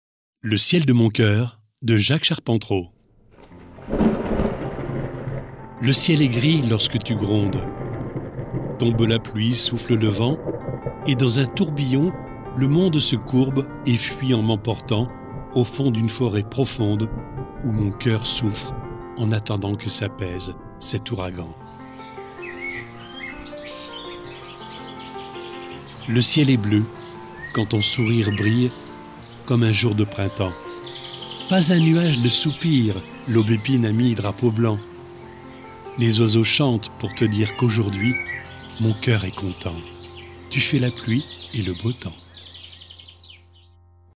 Fichier audio du poème utilisé dans le parcours Le poème mis en voix FRA 3-4